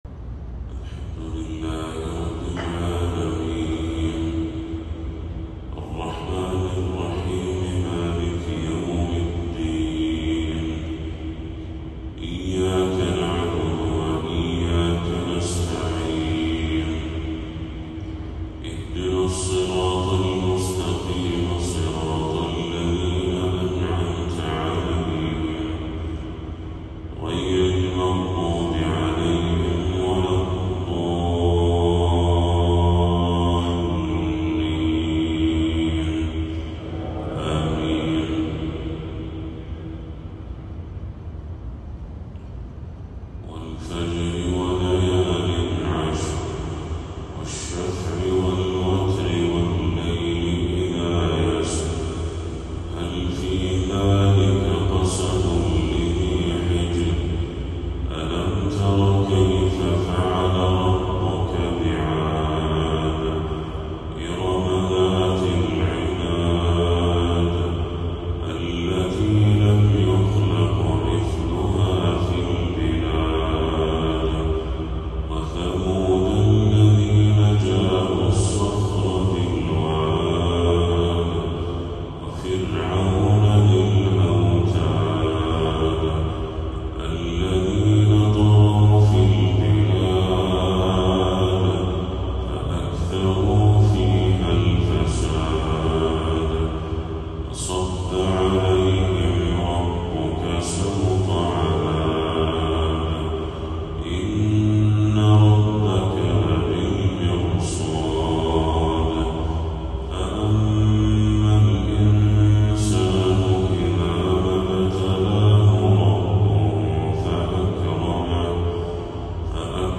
تلاوة لسورتي الفجر والبلد للشيخ بدر التركي | فجر 5 ربيع الأول 1446هـ > 1446هـ > تلاوات الشيخ بدر التركي > المزيد - تلاوات الحرمين